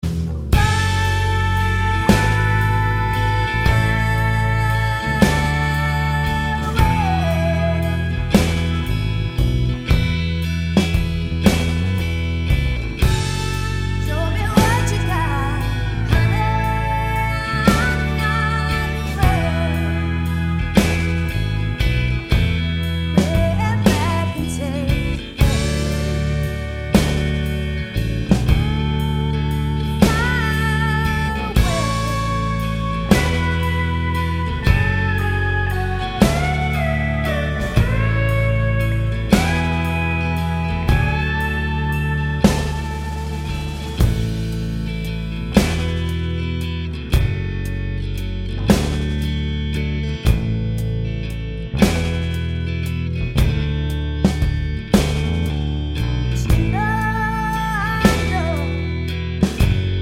no Backing Vocals Country (Male) 4:01 Buy £1.50